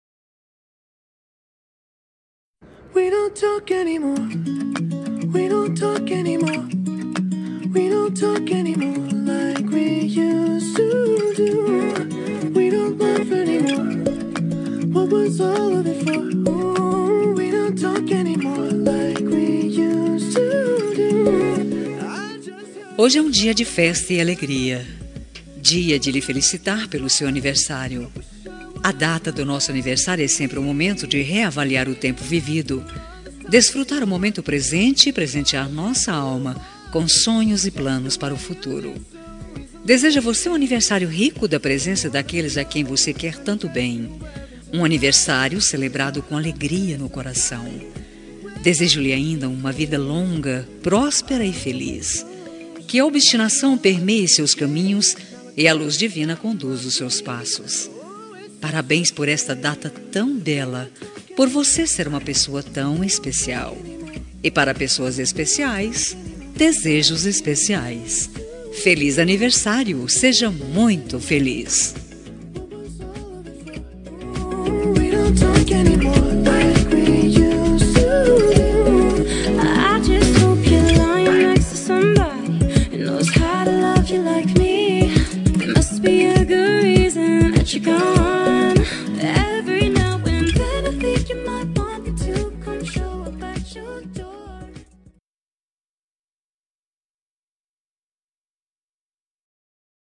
Aniversário de Pessoa Especial – Voz Feminina – Cód: 202216